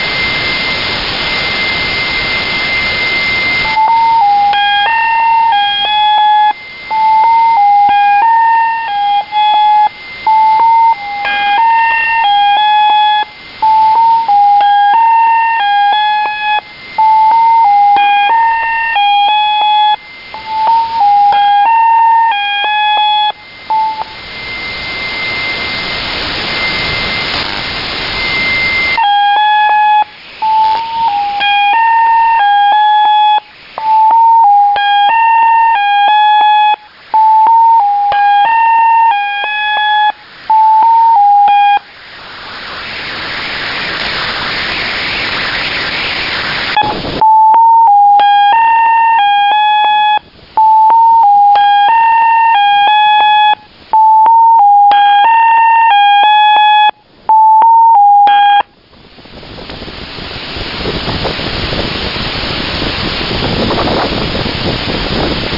Recordings of digital numbers stations